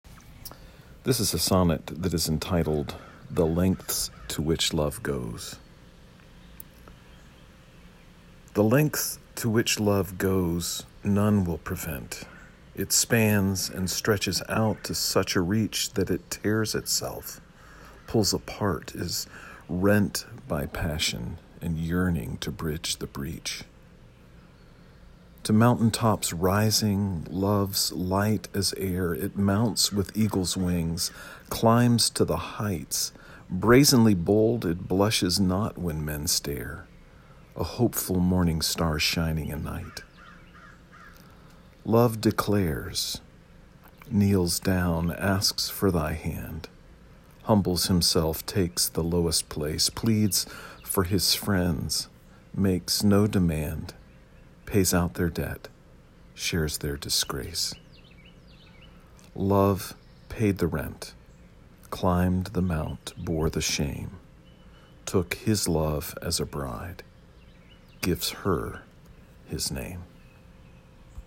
A sonnet for Holy Thursday.
You may listen to me read the sonnet via the player below.